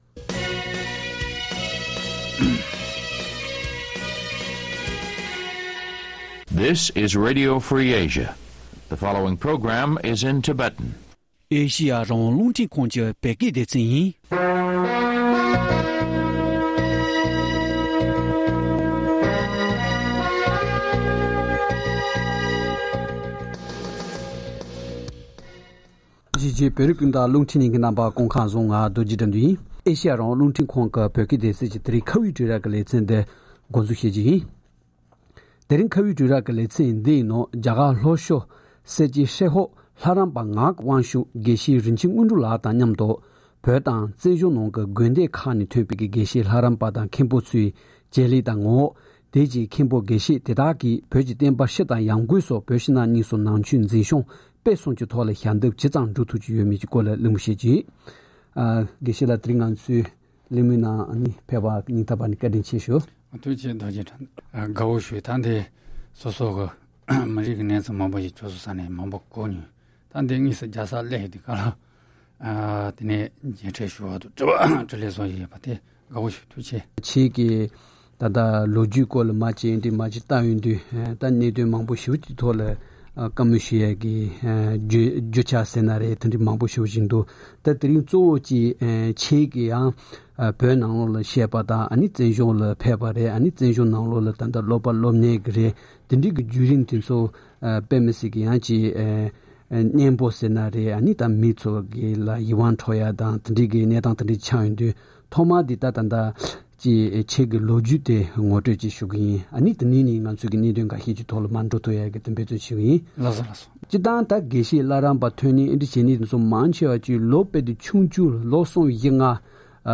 འདི་གལ་རླུང་འཕྲིན་ཁང་གི་སྒྲ་འཇུག་ཁང་ནང་གླེང་བཞིན་པ།